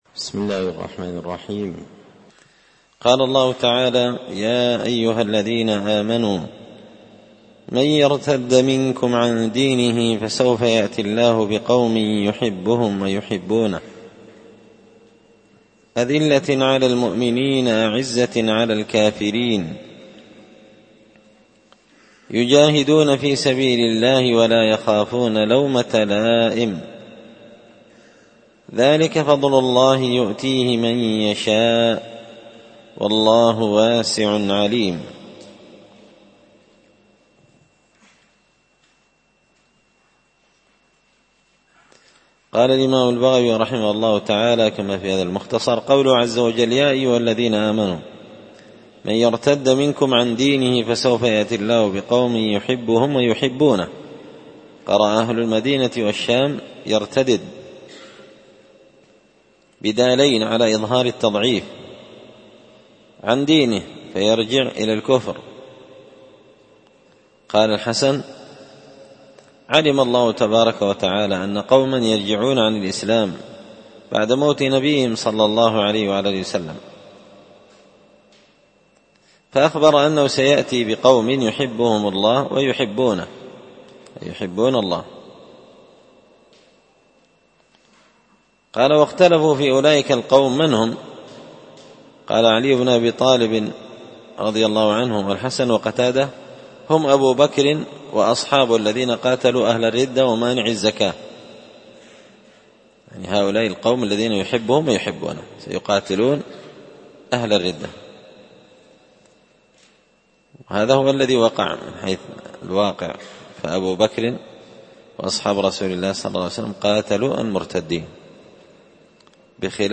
ألقيت هذه الدروس في 📓 # دار _الحديث_ السلفية _بقشن_ بالمهرة_ اليمن 🔴مسجد الفرقان